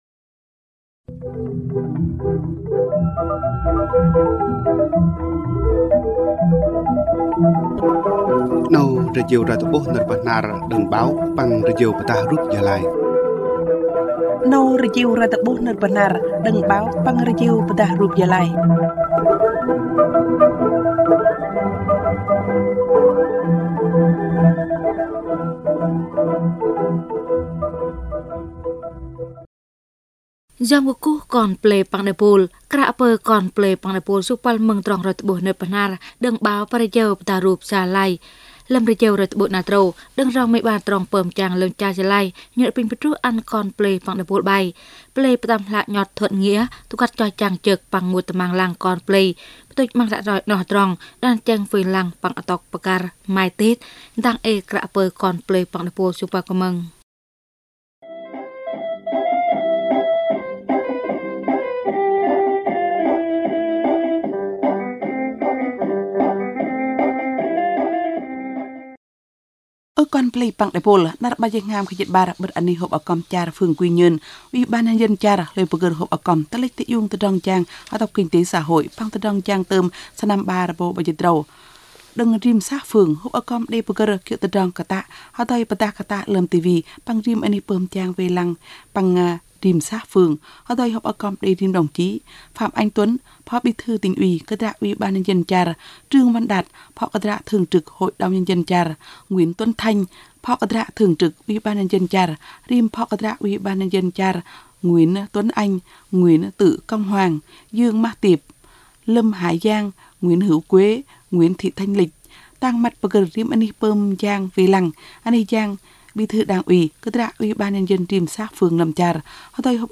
Thời sự PT tiếng Bahnar